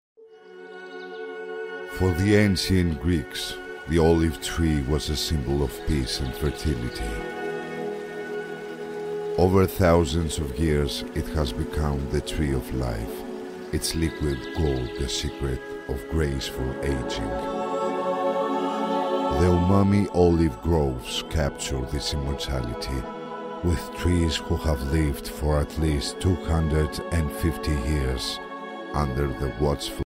J'ai mon propre home studio et je peux vous offrir un son de haute qualité.
Adobe Audition, micro Octava MKL 5000, cabine professionnelle
Âge moyen
Sénior
BarytonBasse
ProfessionnelEntrepriseFiableDe la conversation